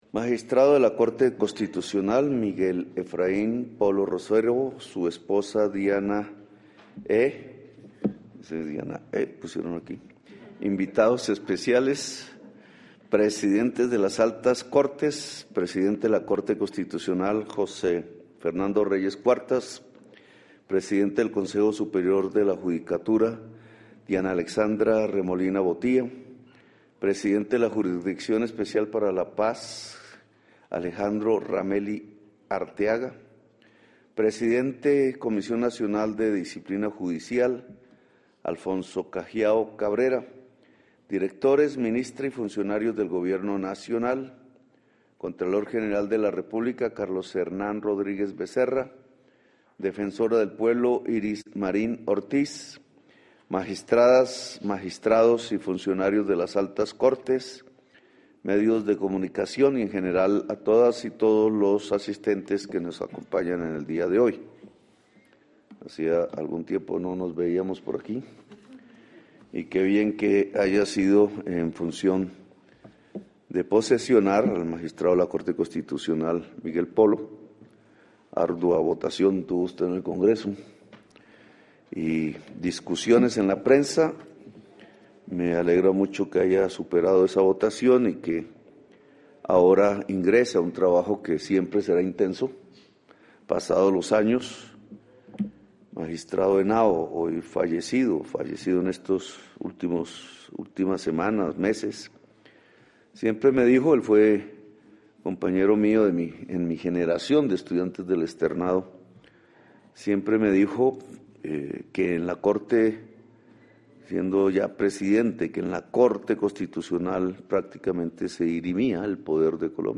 Palabras del presidente Gustavo Petro en la posesión del magistrado de la Corte Constitucional, Miguel Efraín Polo Rosero